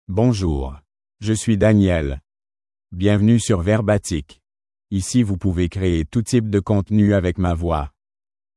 Daniel — Male French (Canada) AI Voice | TTS, Voice Cloning & Video | Verbatik AI
MaleFrench (Canada)
Daniel is a male AI voice for French (Canada).
Voice sample
Male
Daniel delivers clear pronunciation with authentic Canada French intonation, making your content sound professionally produced.